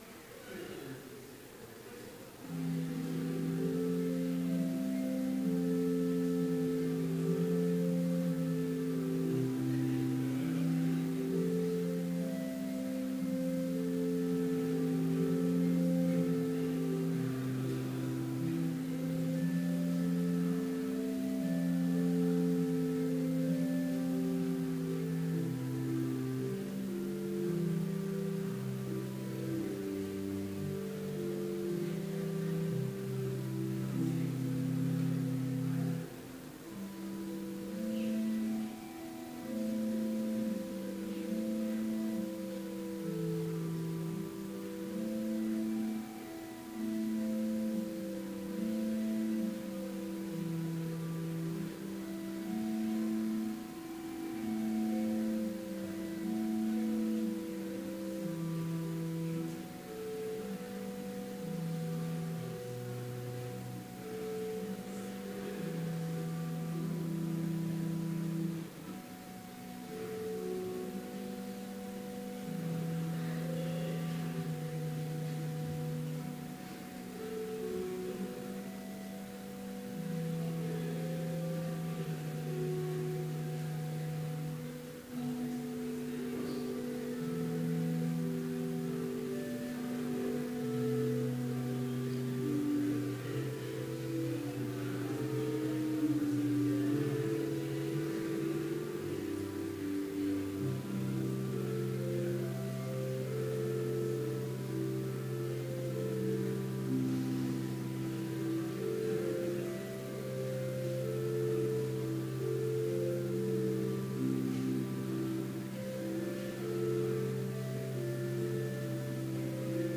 Complete service audio for Chapel - December 14, 2016